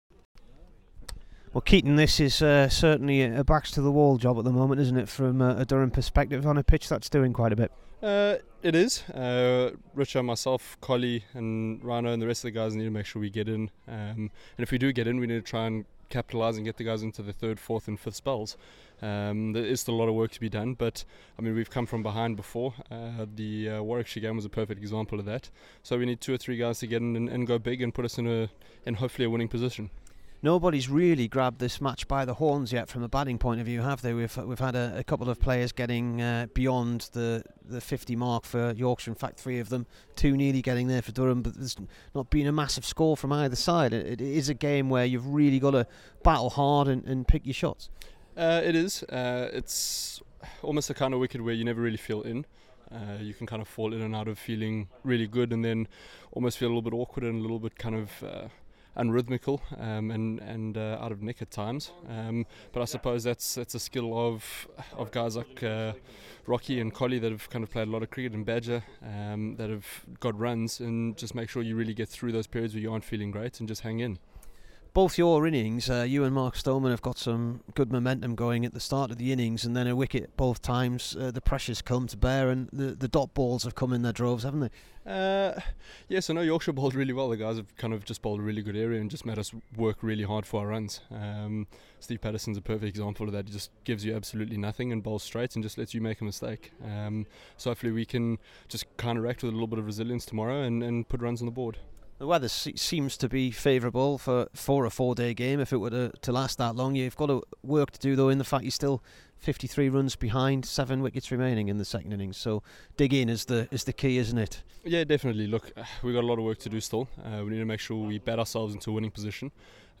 Here is the Durham opener after making 46 not out on day 2 v Yorkshire.